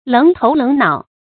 楞頭楞腦 注音： ㄌㄥˊ ㄊㄡˊ ㄌㄥˊ ㄣㄠˇ 讀音讀法： 意思解釋： ①形容魯莽冒失或傻呵呵的樣子。